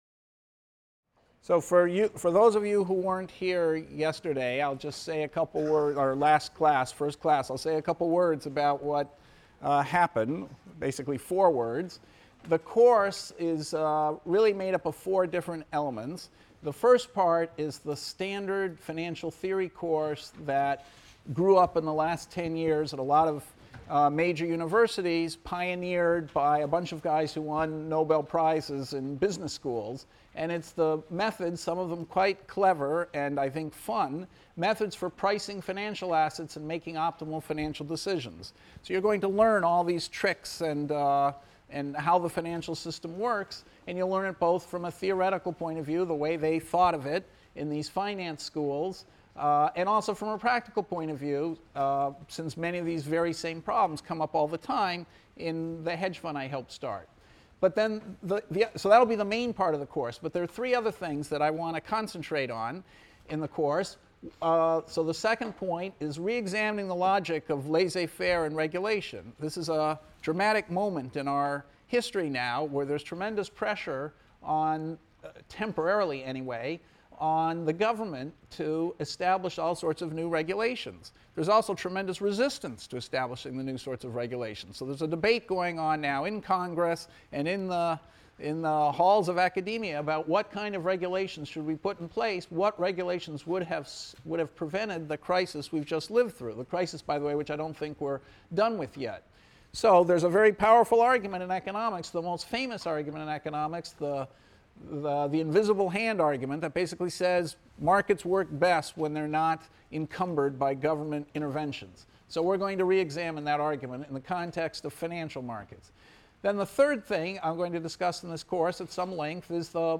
ECON 251 - Lecture 2 - Utilities, Endowments, and Equilibrium | Open Yale Courses